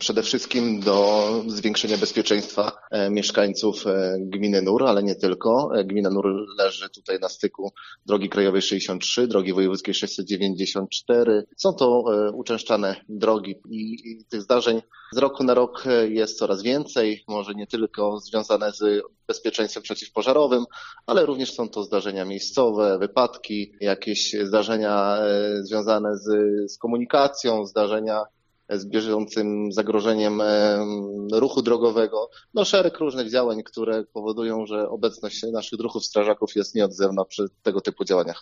To bardzo potrzebna inwestycja dla naszej jednostki OSP –  mówi wójt gminy Nur, Rafał Kruszewski: